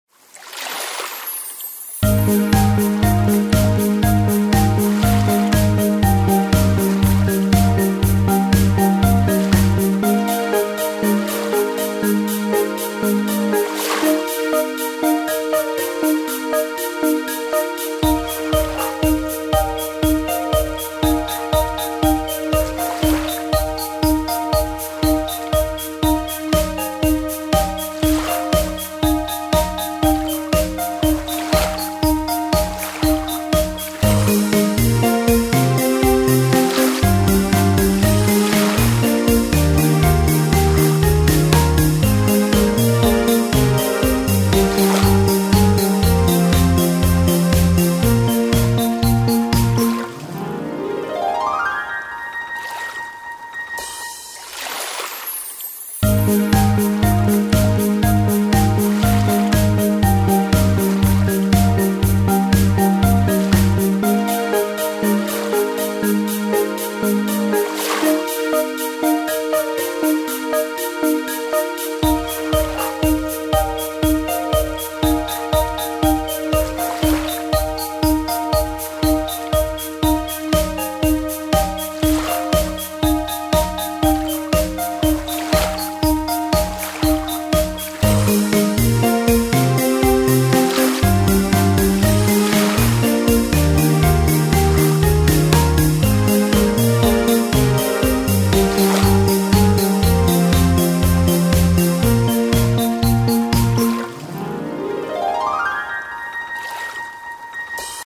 BGM
アップテンポショート明るい穏やか